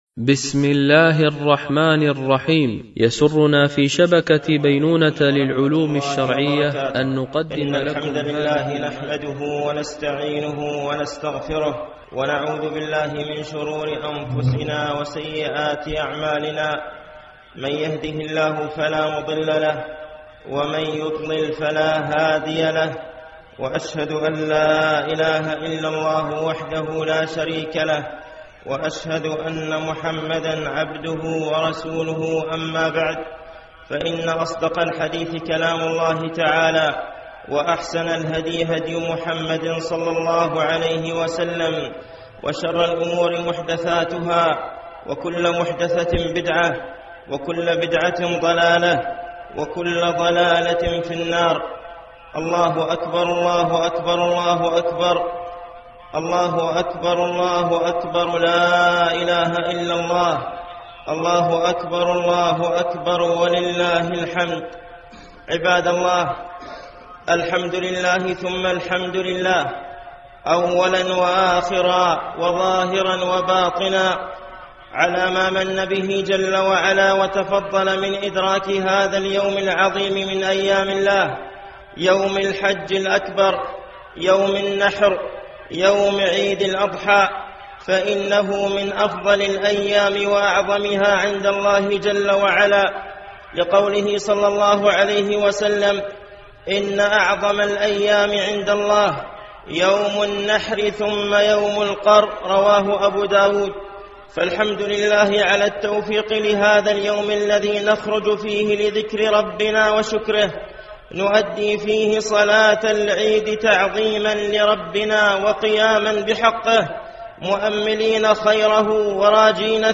خطبة عيد الأضحى عام 1434